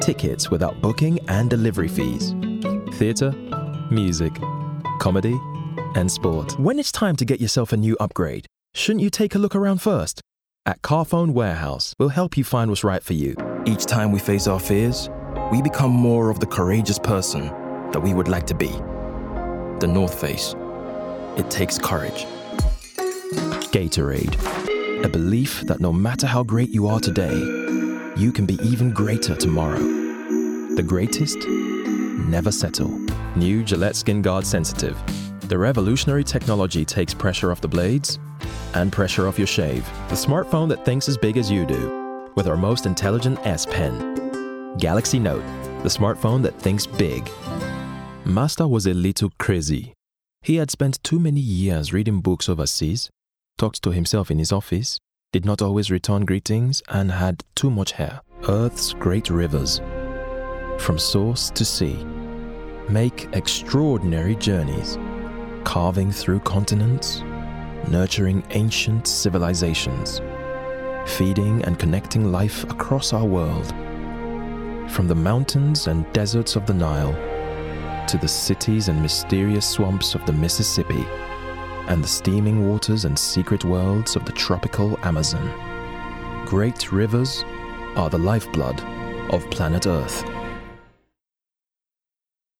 Showreel
African, American, Straight
Showreel, Cool, Conversational, Rich, Deep